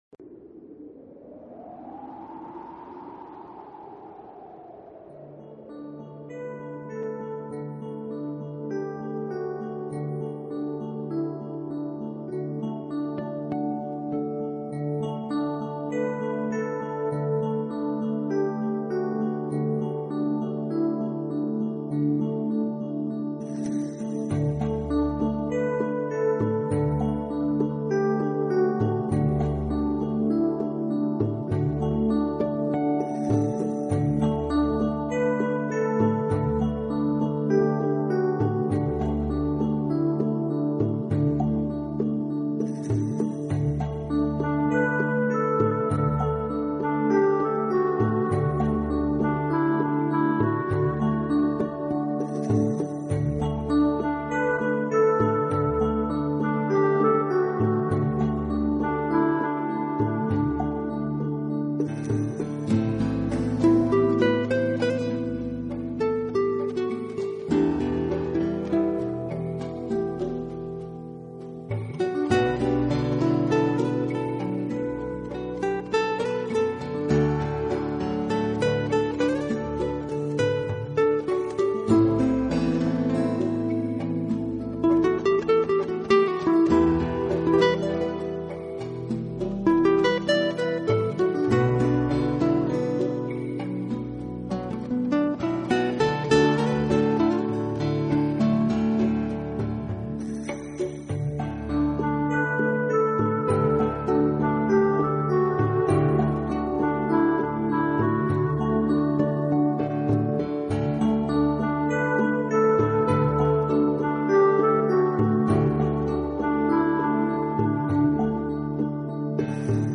Genre..........: New Age
helps create a relaxing and inspiring atmosphere.